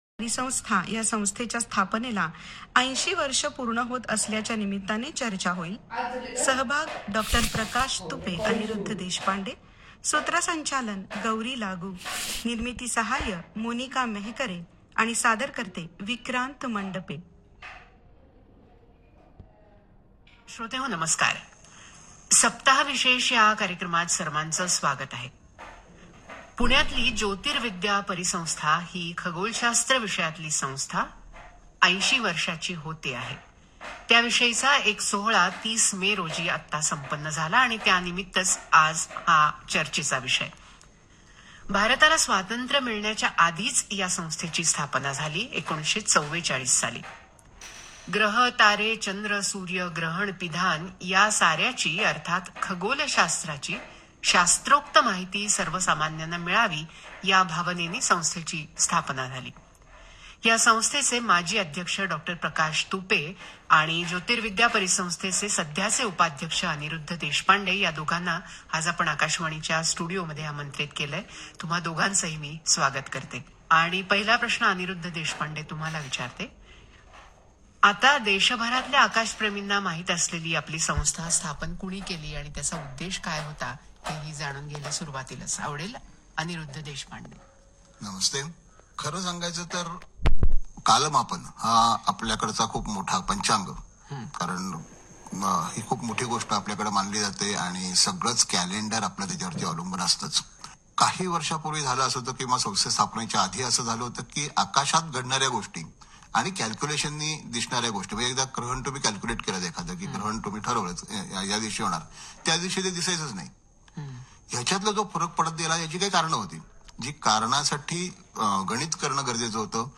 सप्ताह विशेष चर्चा या कार्यक्रमात ऐकू या, ‘ज्योतिर्विद्या परिसंस्था’ या संस्थेला ८० वर्ष पूर्ण होत आहेत त्यानिमित्त चर्चा.
प्रसारण – रविवार, दि. ८ जून २०२५ रोजी सकाळी ९:३० वाजता आकाशवाणी पुणे केंद्रावरून आणि सोमवारी दुपारी १:३० वाजता पुणे विविधभारती १०१ एफएम केंद्रावरून.
JVP-Program-on-Aakashwani-Pune.mp3